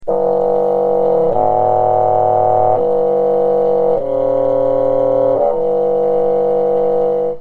Звуки фагота
Фагот – уникальный деревянный духовой инструмент с глубоким тембром, который часто используют в симфонических оркестрах.
Мрачная нота